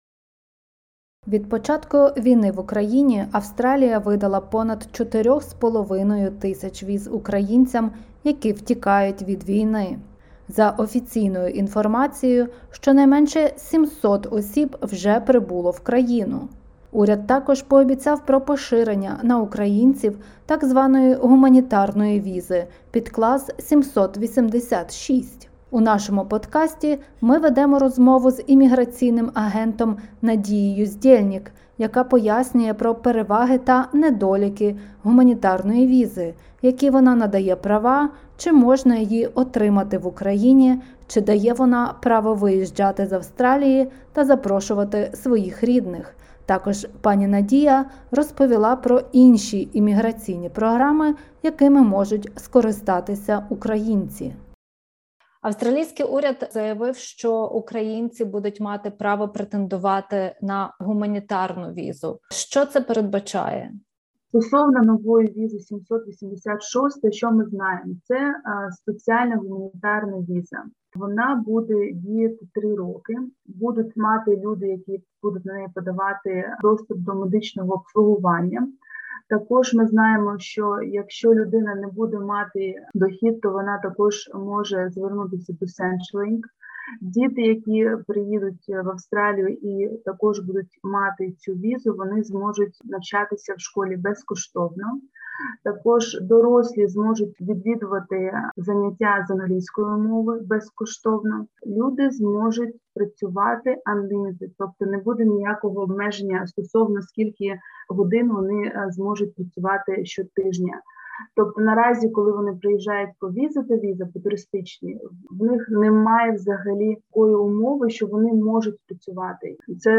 SBS Українською